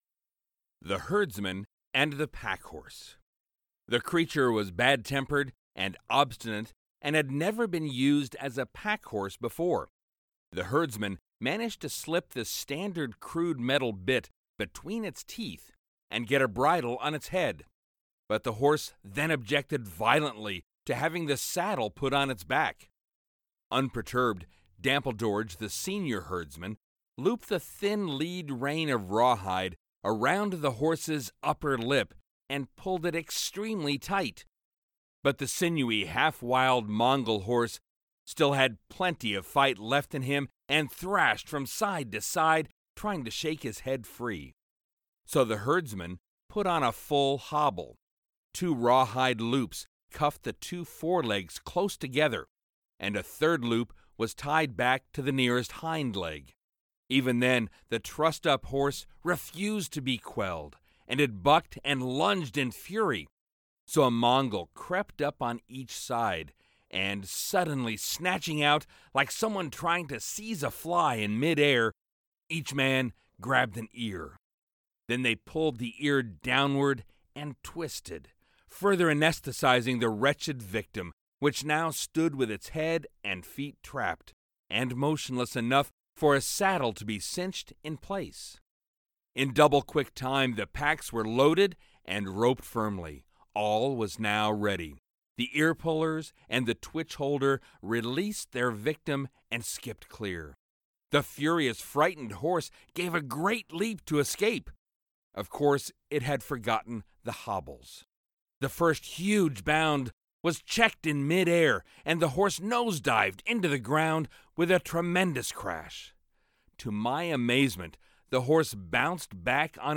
Male
Adult (30-50), Older Sound (50+)
Audiobooks
Believable And Sincere Audiobo